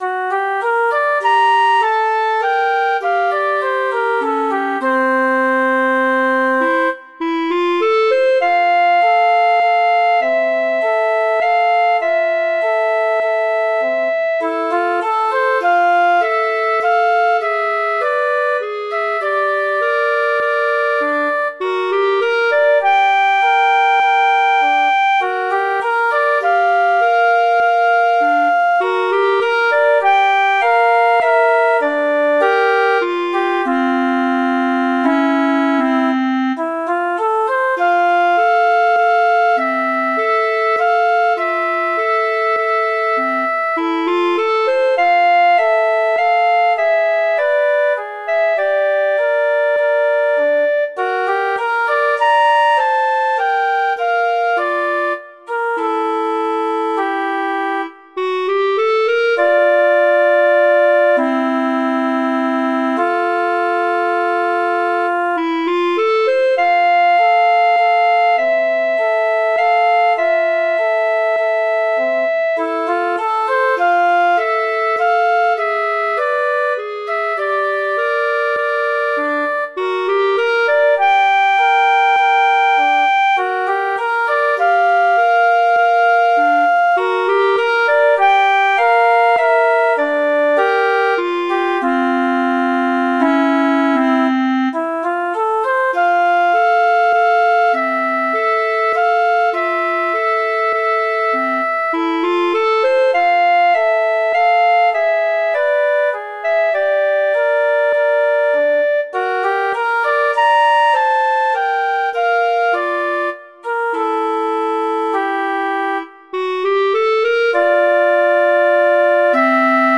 Instrumentation: flute & clarinet
Note Range: C4-A#5
jazz, traditional, wedding, standards, festival, love
F major
♩=100 BPM